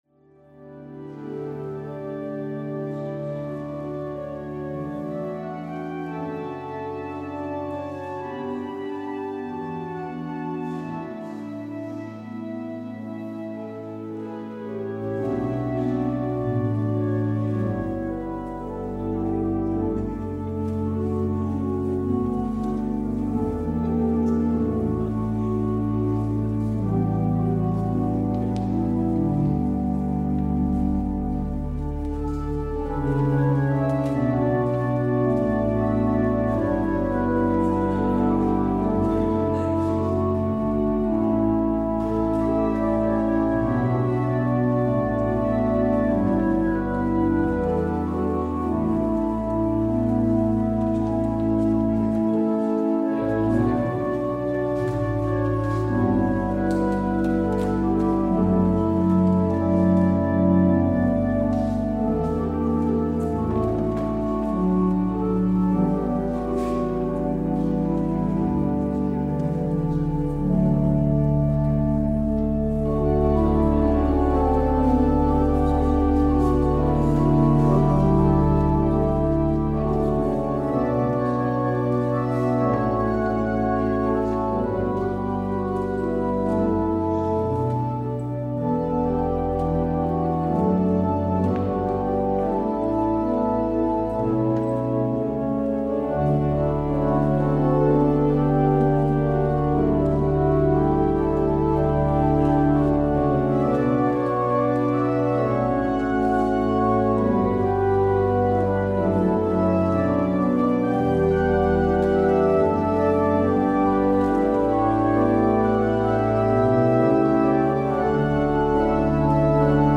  Beluister deze kerkdienst hier: Alle-Dag-Kerk 26 februari 2025 Alle-Dag-Kerk https